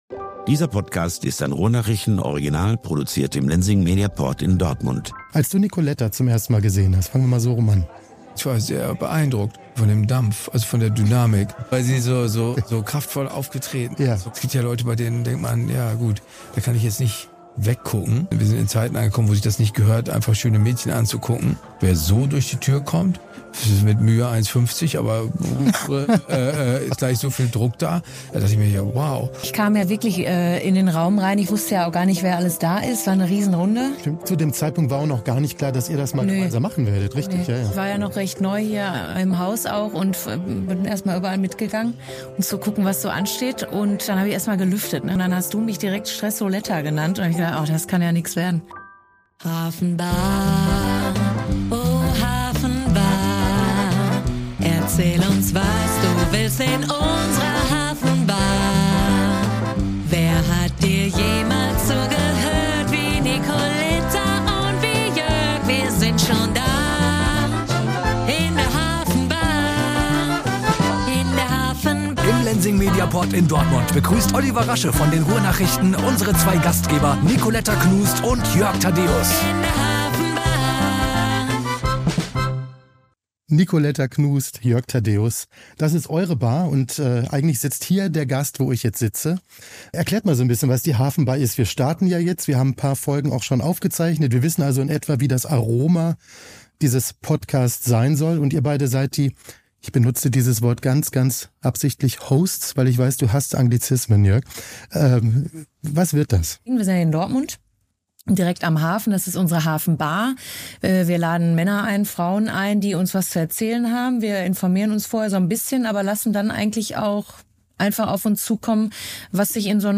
Die zwei nehmen Platz und geben erste Einblicke, welche Gäste, Themen und Geschichten die Hafenbar in den nächsten Folgen für euch bereithält. Der Ruhrpott bekommt seinen Talk.